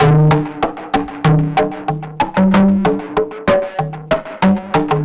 Index of /musicradar/analogue-circuit-samples/95bpm/Drums n Perc
AC_PercB_95-01.wav